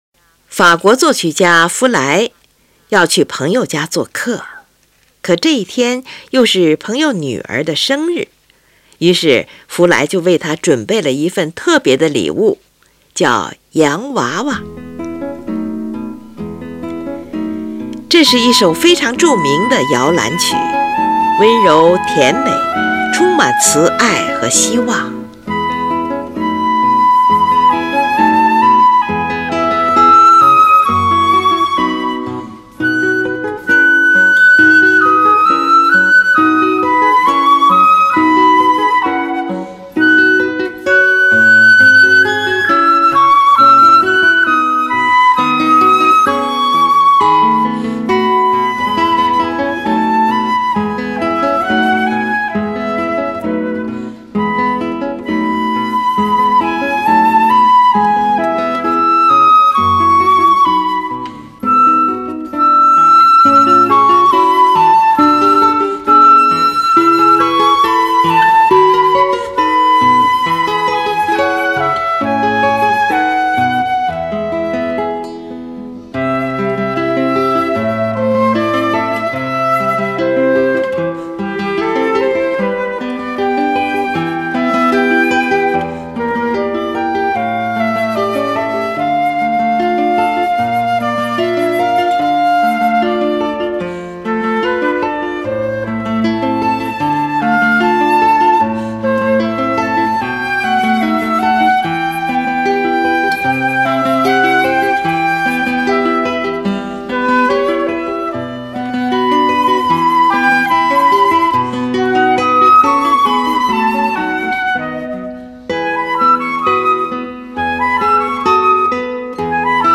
Suite for Flute and Guitar
这是一首由长笛与吉他合奏的乐曲，共分为四个乐章。第一乐章，悠缓又不沉闷的中板。